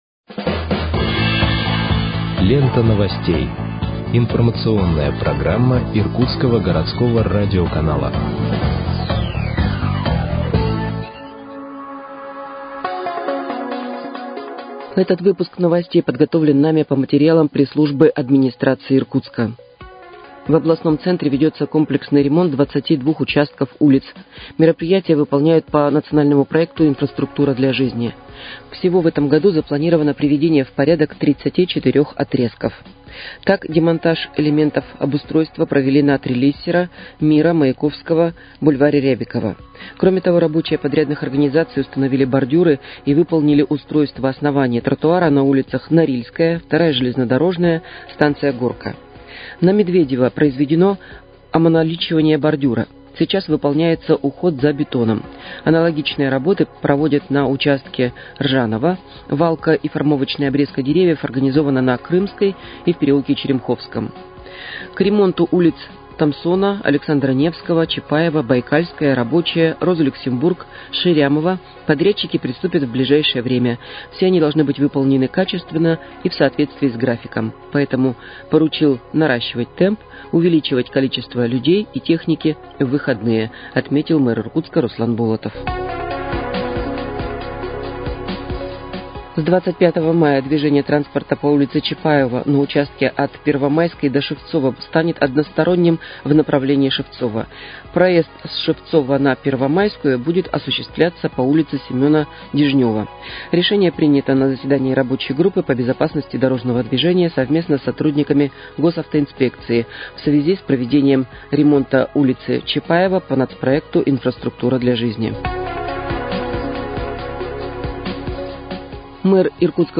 Выпуск новостей в подкастах газеты «Иркутск» от 23.05.2025 № 2